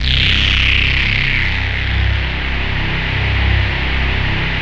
BASS18  01-L.wav